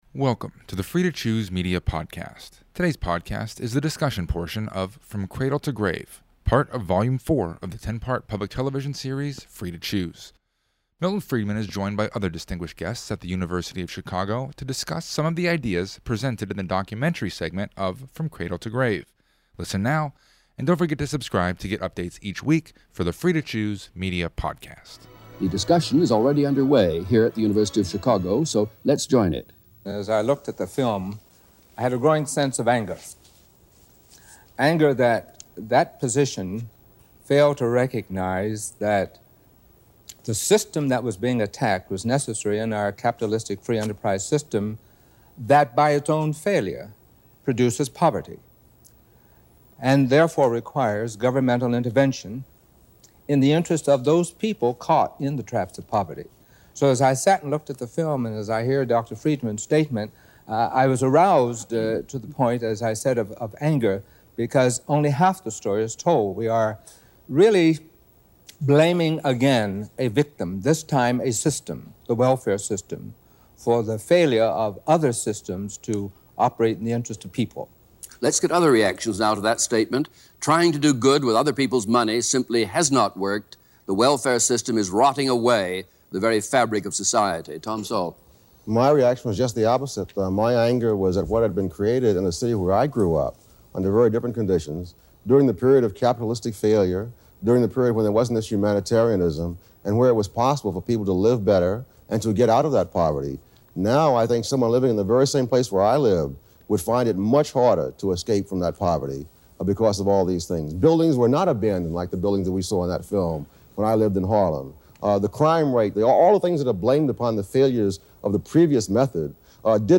Episode 136 – Free To Choose 1980 – From Cradle to Grave – Discussion